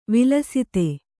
♪ vilasite